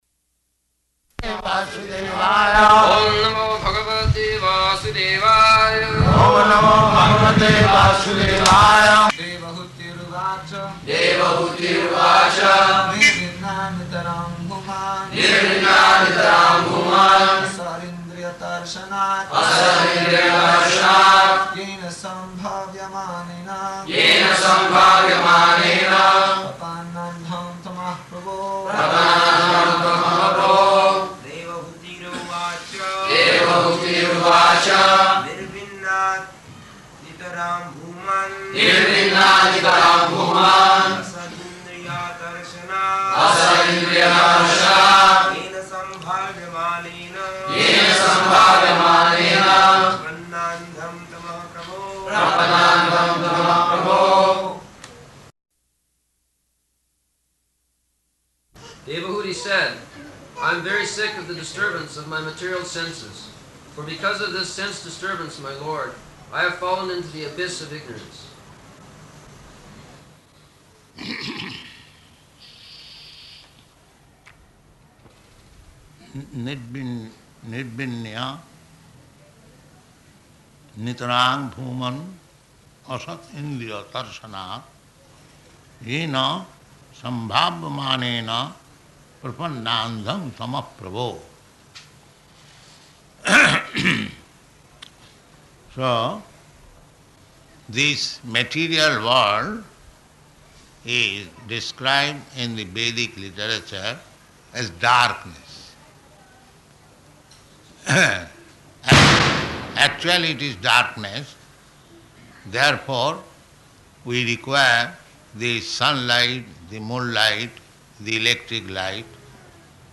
November 7th 1974 Location: Bombay Audio file